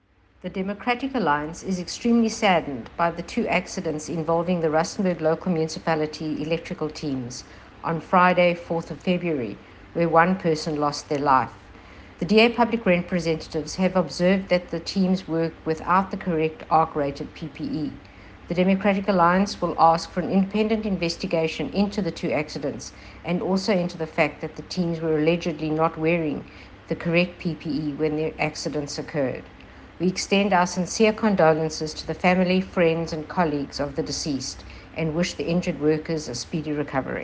Note to Editors: Please find attached soundbite in
English by DA Constituency Head: Rustenburg Local Municipality, Cheryl Phillips MP.